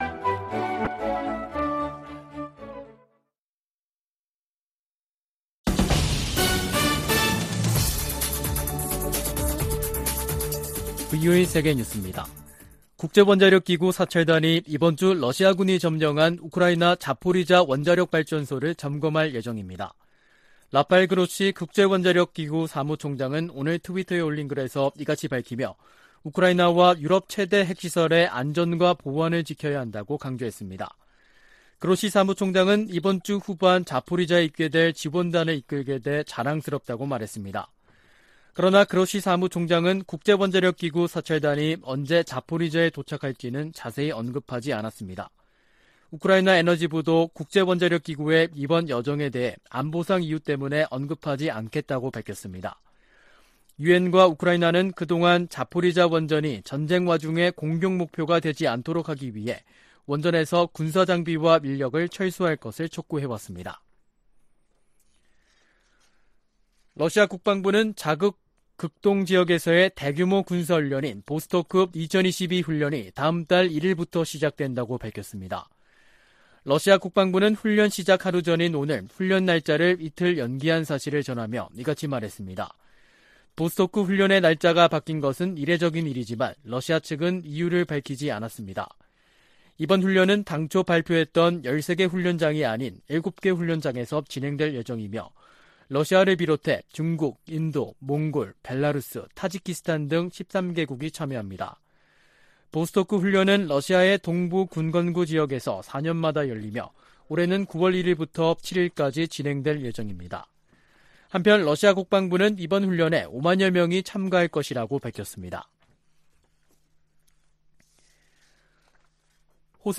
VOA 한국어 간판 뉴스 프로그램 '뉴스 투데이', 2022년 8월 29일 3부 방송입니다. 북한이 핵실험 준비를 마치고 한국을 향해 보복성 대응을 언급하고 있다고 한국 국방부 장관이 말했습니다. 제10차 핵확산금지조약 (NPT) 평가회의가 러시아의 반대로 최종 선언문을 채택하지 못한 채 끝났습니다.